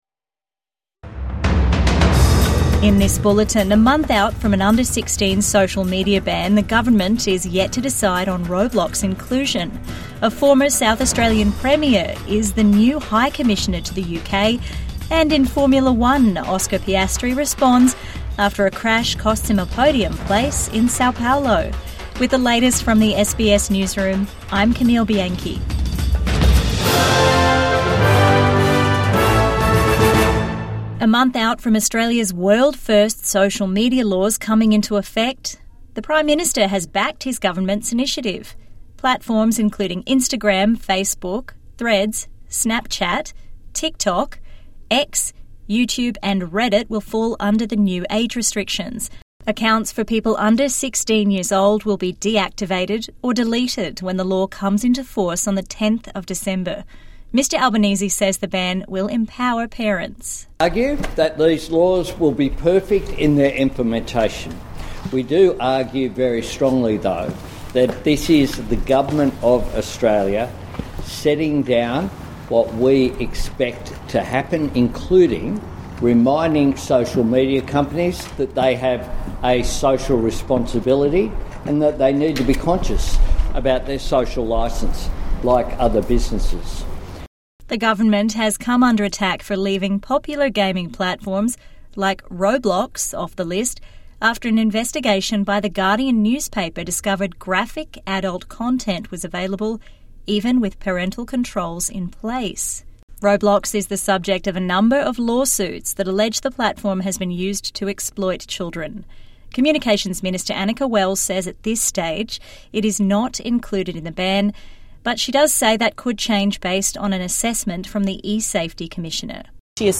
In this bulletin, a month out from the under 16 social media ban, the government is yet to decide on Roblox inclusion, a former South Australian Premier will be the new High Commissioner to the UK. And in Formula 1, a downcast Oscar Piastri responds after a crash costs him a podium place in Sao Paulo.